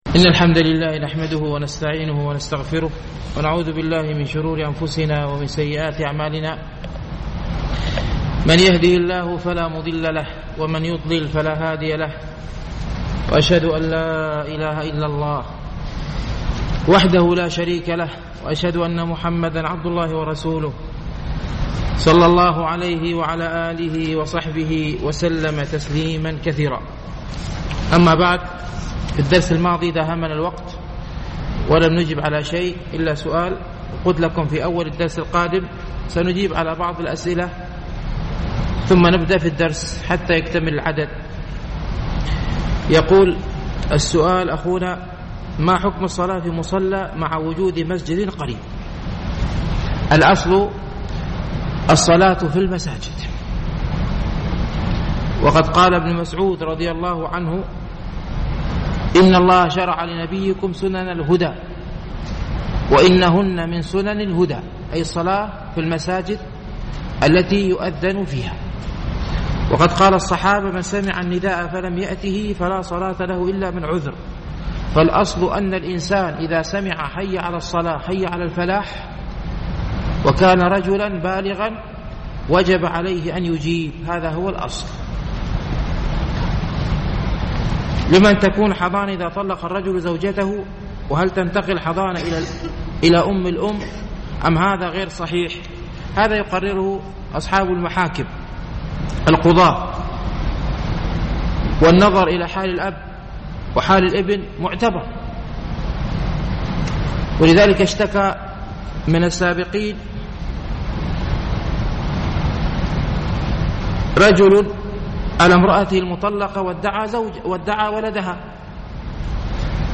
شرح رياض الصالحين ـ الدرس الخمسون